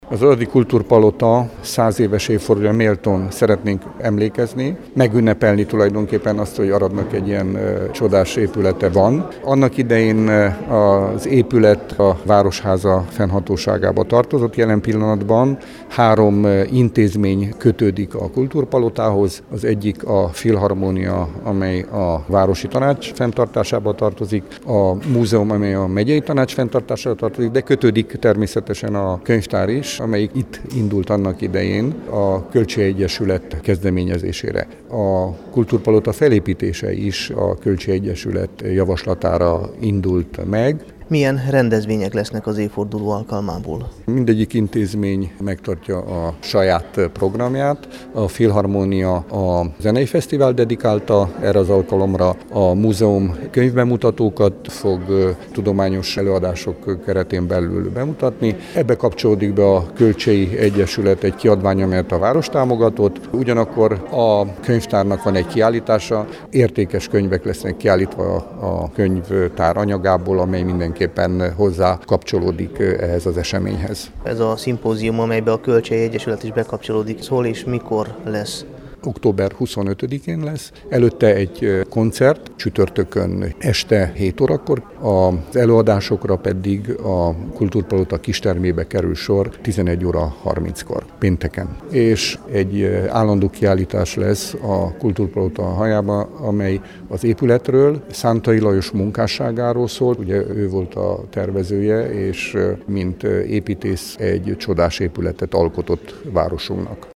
Őt kérte mikrofon elé a sajtótájékoztató után